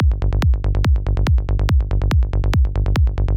Является ли это проблема с фазой бочки и баса?
) Вложения Kick&Bass.wav Kick&Bass.wav 582,4 KB · Просмотры: 231